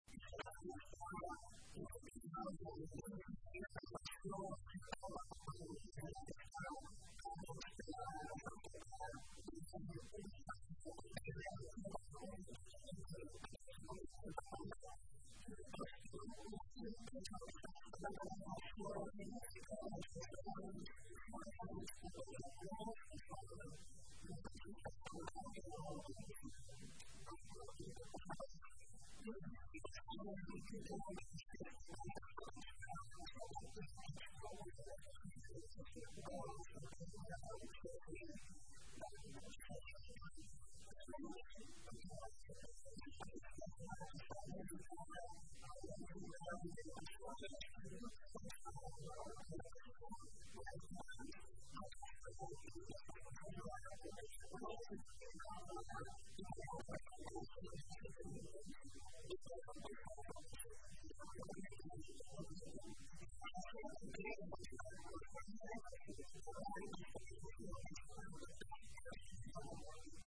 En dóna més detalls, la regidora de Festes de l’Ajuntament de Tordera, Sílvia Català.